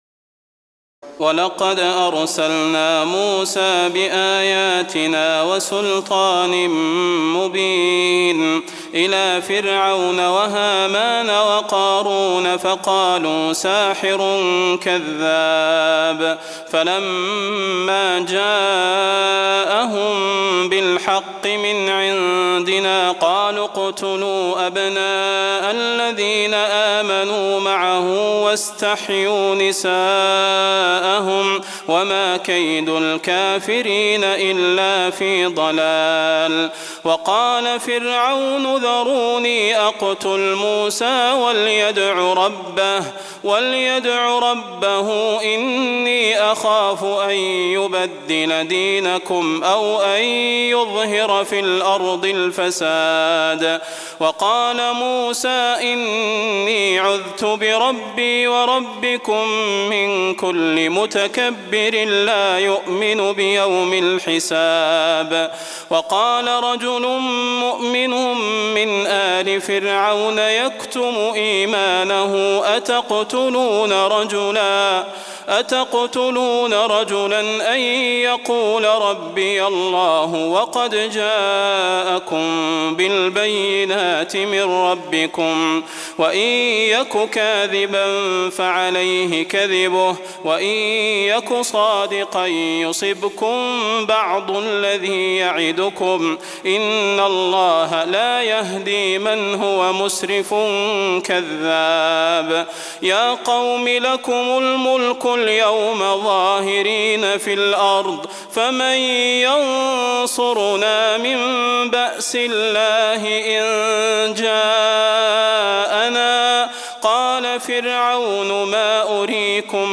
تراويح ليلة 23 رمضان 1423هـ من سور غافر (23-85) وفصلت (1-8) Taraweeh 23 st night Ramadan 1423H from Surah Ghaafir and Fussilat > تراويح الحرم النبوي عام 1423 🕌 > التراويح - تلاوات الحرمين